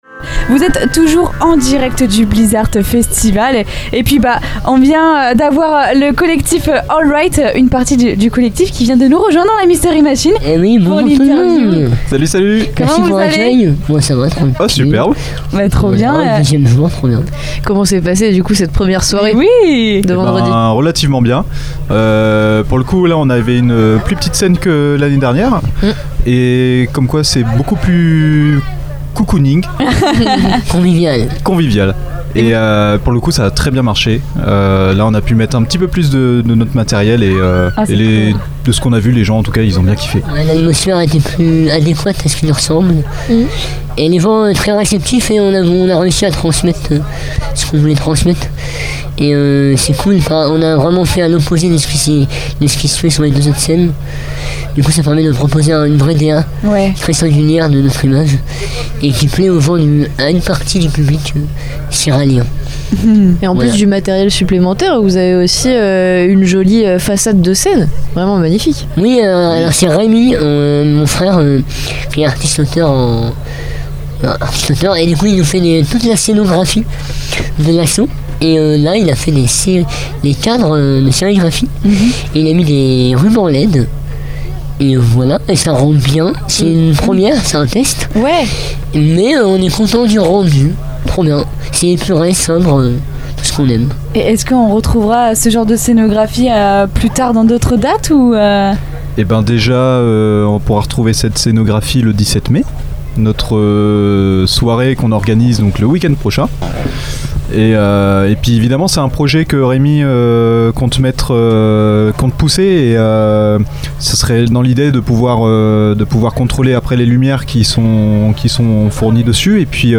Le 10 mai dernier, au cœur du Blizz’Art Festival à Ciral, nous avons eu le plaisir de rencontrer Hole Right, un collectif artistique engagé mêlant musique, performances, visuels et réflexion sociale. Installés dans la Mystery Machine (studio radio aménagé dans une camionnette), les membres du collectif se sont prêtés au jeu de l’interview, diffusée en direct sur Radio Pulse et Radio Coup de Foudre.